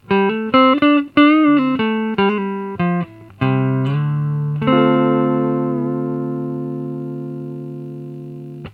Записано все на советский конденсаторный микрофон (или в линию).
чистый звук через комбик без преампа, с которым я обычно играю (handmade чемодан с лампами, овердрайвом и разрывом для паралельных эффектов ).